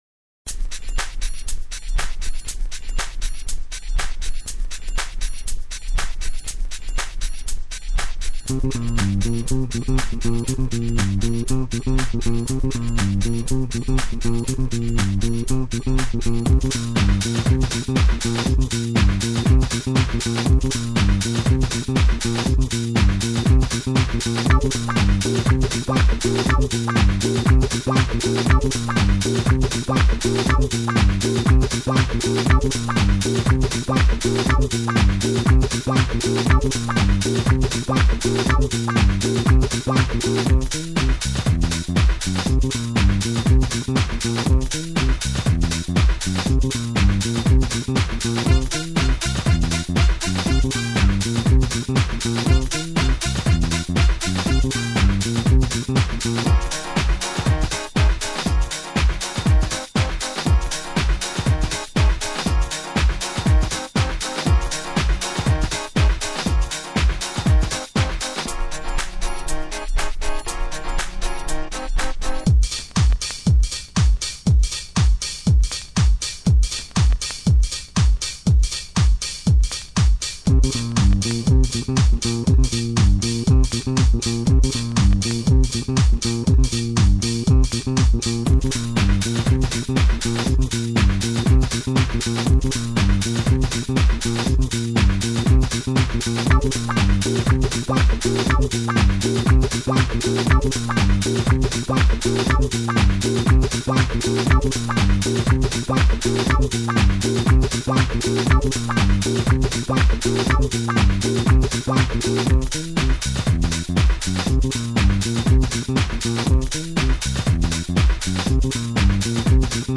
(House)